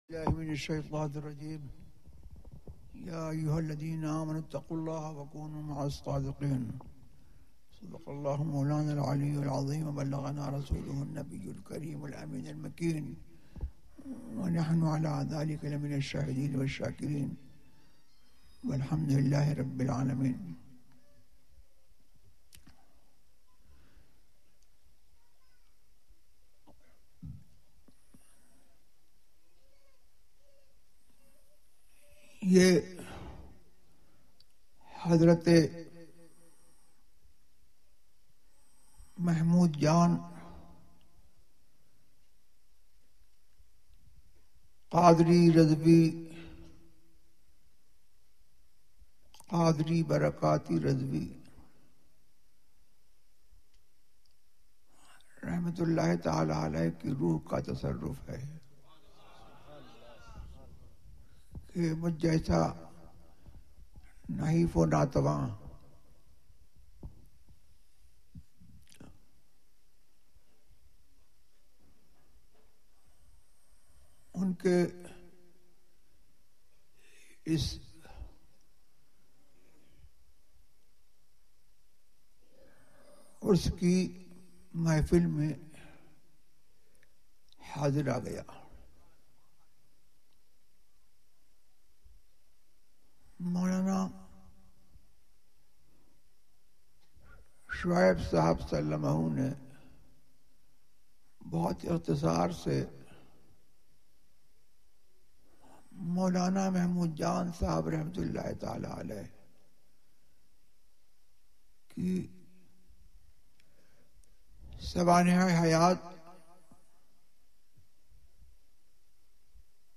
عرس حضرت محمود جان خان رضوی ZiaeTaiba Audio میڈیا کی معلومات نام عرس حضرت محمود جان خان رضوی موضوع تقاریر آواز تاج الشریعہ مفتی اختر رضا خان ازہری زبان اُردو کل نتائج 929 قسم آڈیو ڈاؤن لوڈ MP 3 ڈاؤن لوڈ MP 4 متعلقہ تجویزوآراء